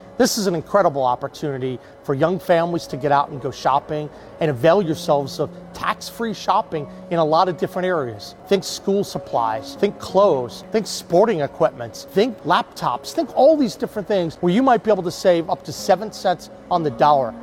Governor Patrick Morrisey was in Bridgeport to talk about the event…